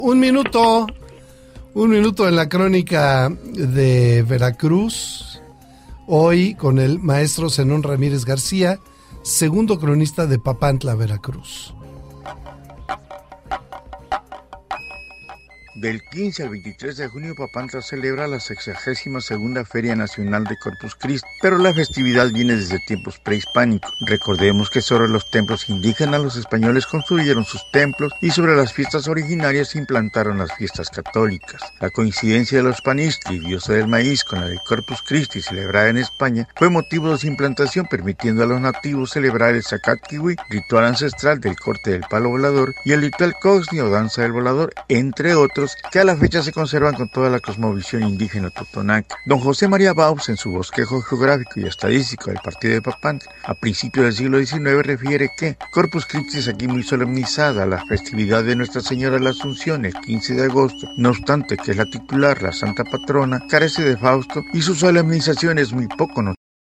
Aquí les compartimos las grabaciones sacadas al aire de la sección del noticiero referido.